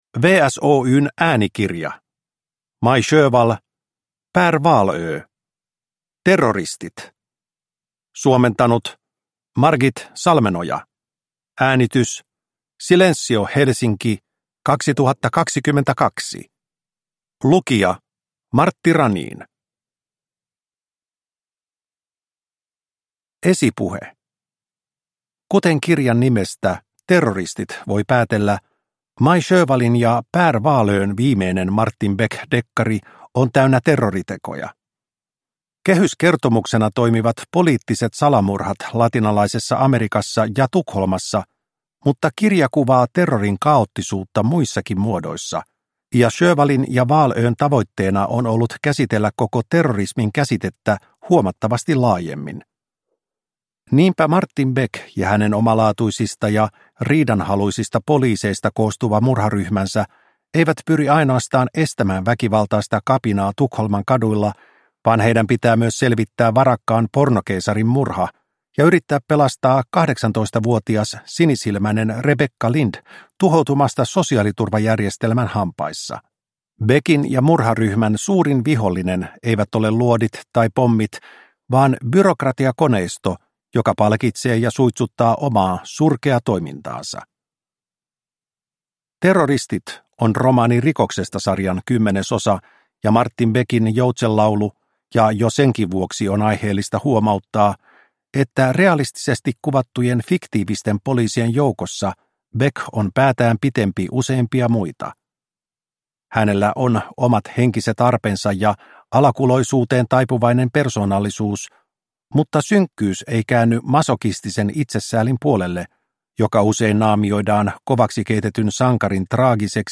Terroristit – Ljudbok – Laddas ner